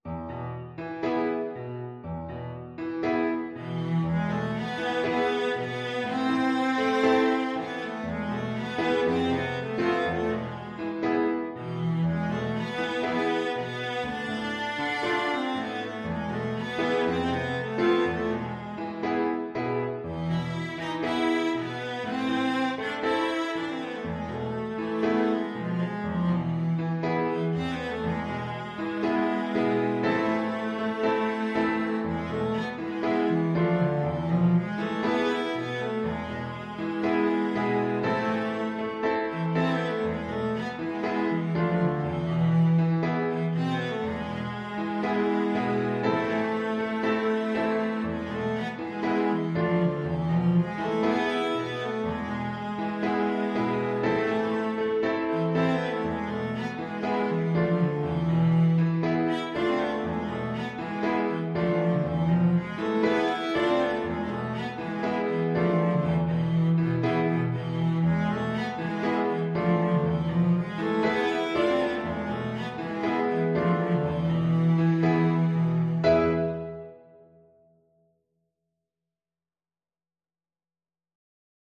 Traditional Trad. Araber Tanz (Klezmer) Cello version
Cello
A minor (Sounding Pitch) (View more A minor Music for Cello )
4/4 (View more 4/4 Music)
Allegro moderato =120 (View more music marked Allegro)
Traditional (View more Traditional Cello Music)